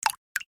Genel olarak telefonun güçlü özelliklerini yansıtan polifonik ve güçlü tonların bulunduğu bildirim seslerinin firmanın yıllardır süregelen seslerin modern yorumlarını içermektedir.
Bubbles
bubbles.mp3